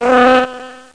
00126_Sound_Abeille.mp3